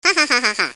Laugh-Hahaha.mp3